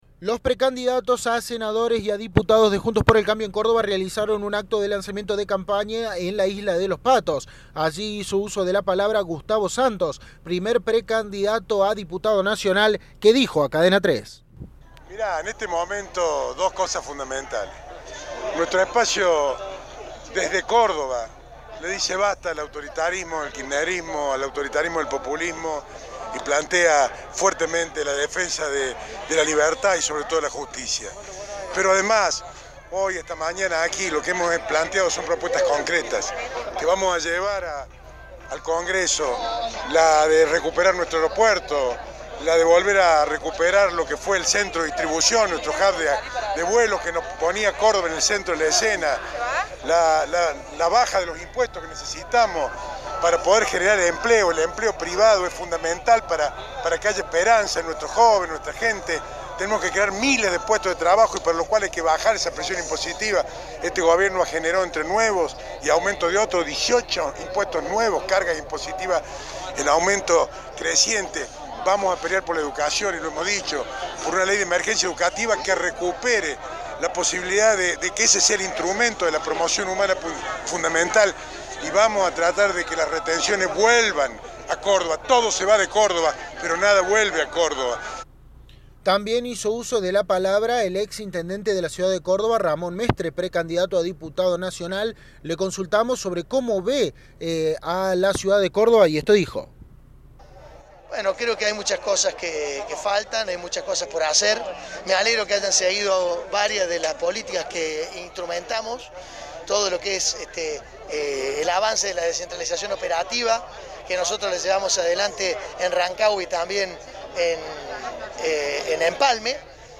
El ex ministro de turismo y actual precandidato a diputado, Gustavo Santos, dialogó con Cadena 3 y enumeró algunas de las propuestas que llevarán adelante desde el espacio.